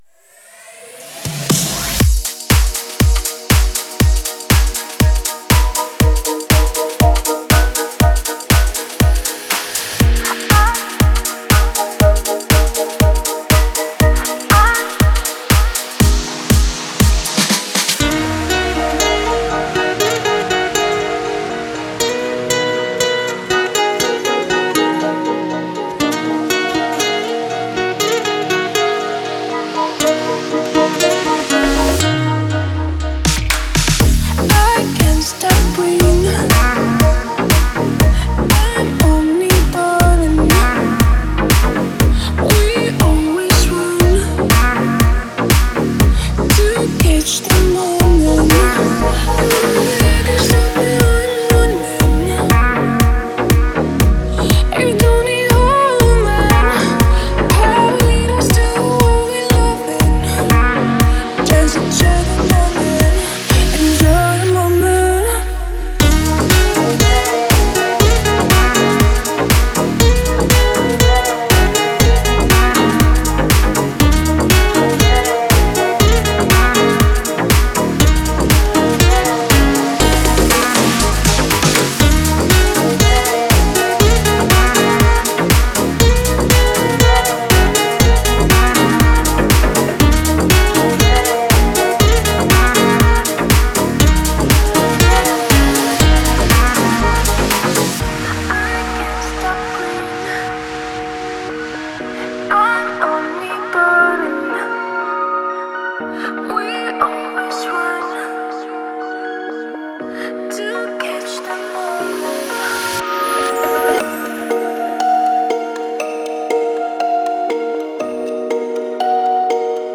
это трек в жанре поп с элементами электронной музыки